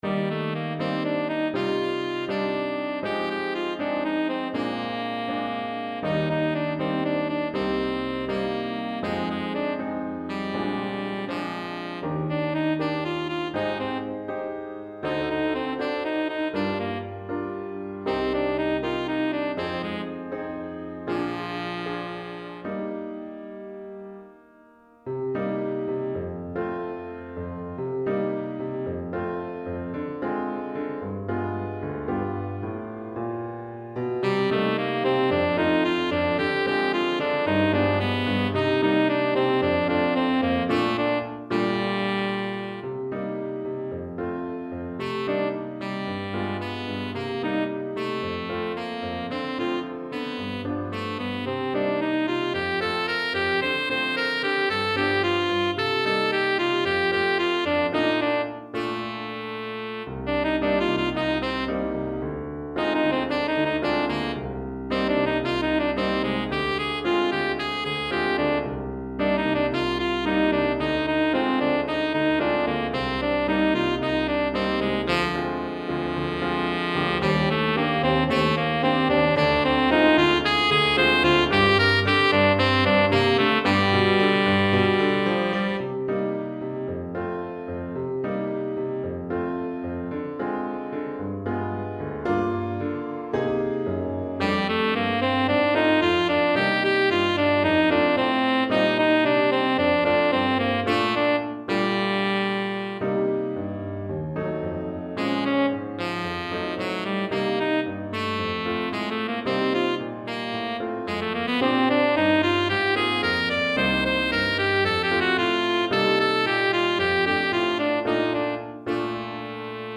Saxophone Ténor et Piano